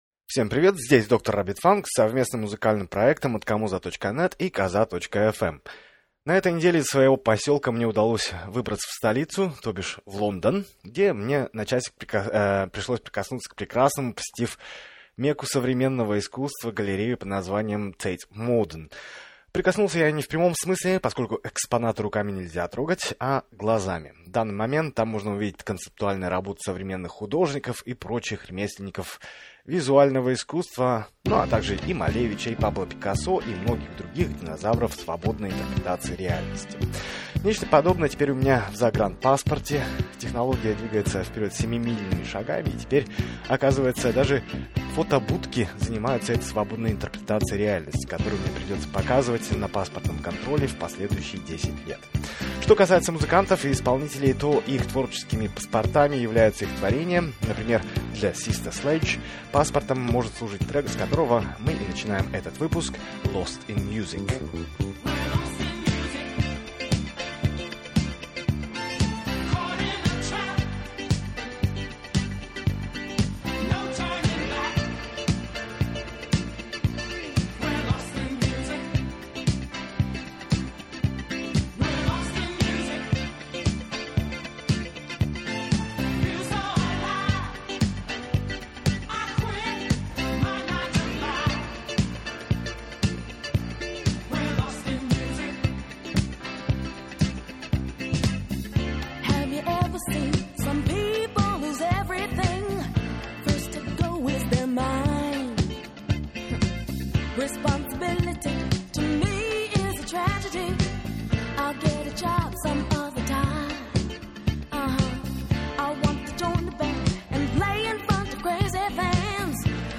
А ведь если не знать английский – романтичный медляк.